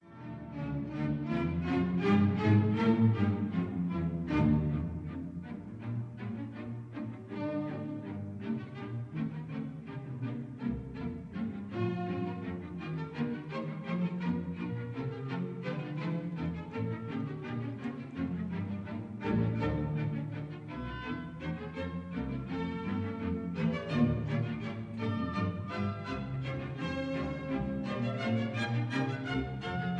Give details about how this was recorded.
This is a stereo recording